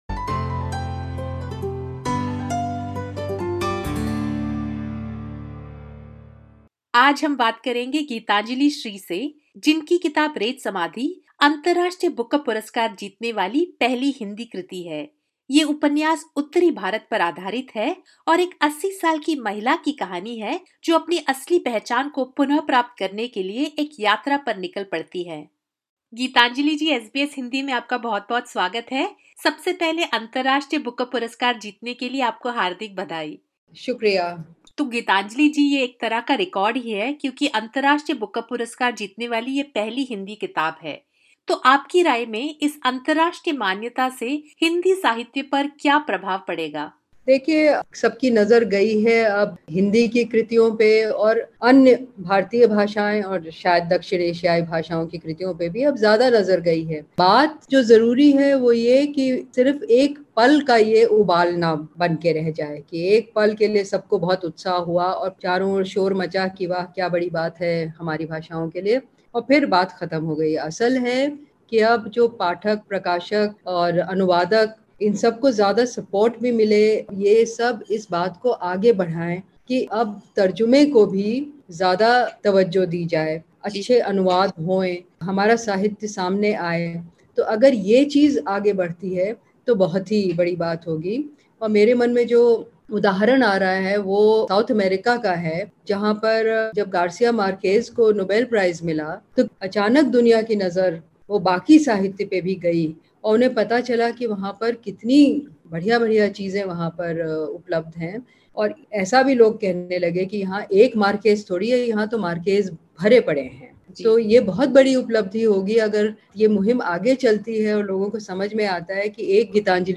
इस पॉडकास्ट में सुनिए गीतांजलि श्री ने हिन्दी भाषा को लेकर क्या कहा है।